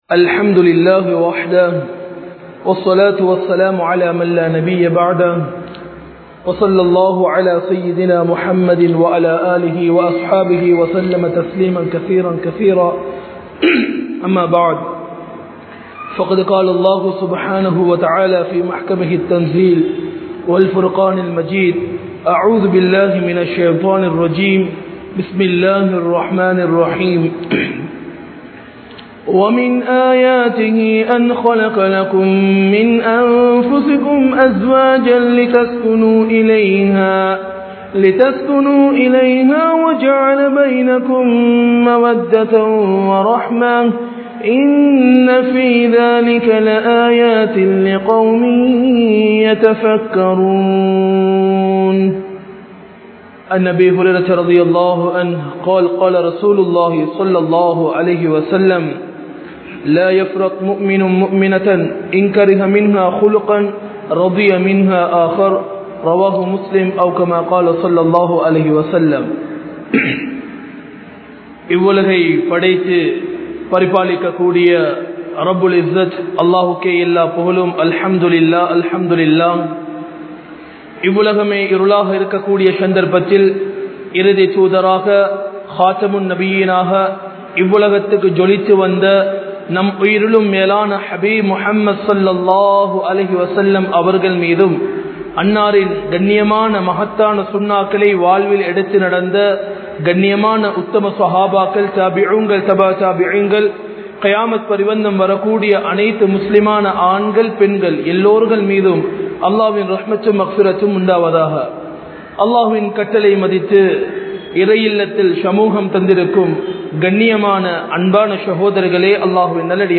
Kudumba Vaalkaiel Amaithi Veanduma? (குடும்ப வாழ்க்கையில் அமைதி வேண்டுமா?) | Audio Bayans | All Ceylon Muslim Youth Community | Addalaichenai
Mallawapitiya Jumua Masjidh